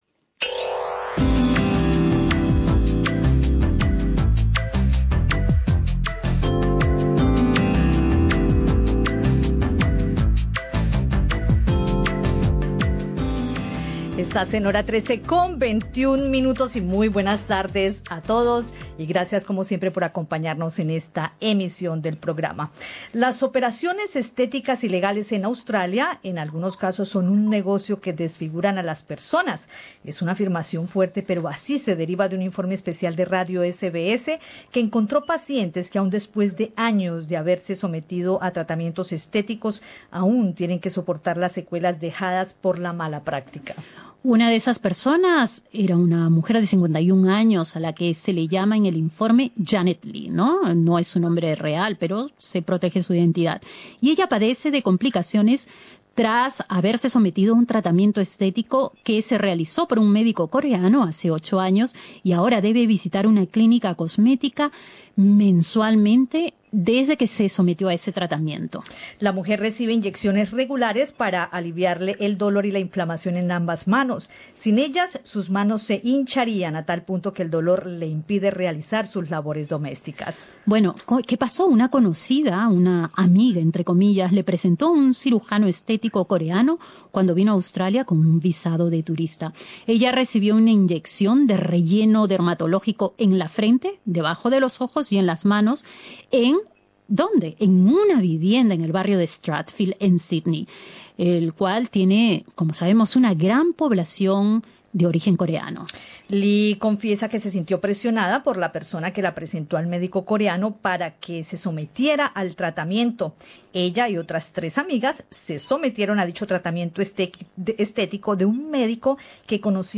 Conversamos con el cirujano plástico peruano